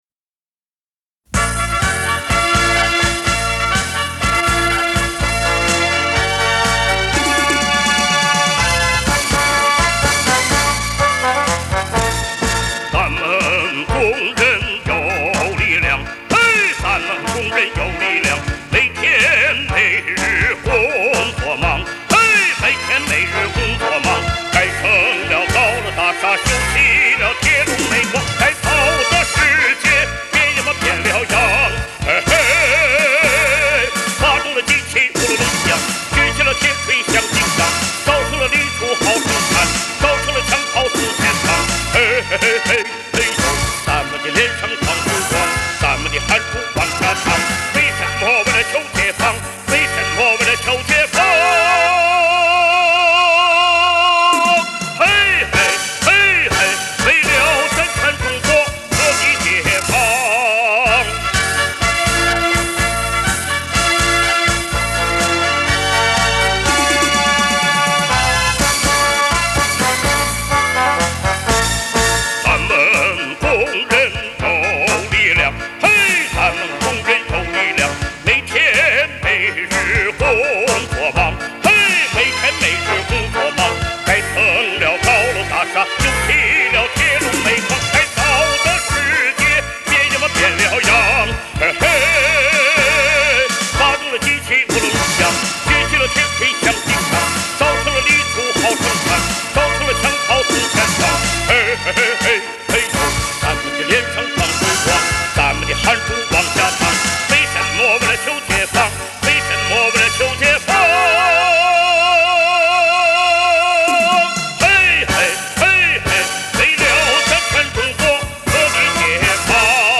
著名男中音歌唱家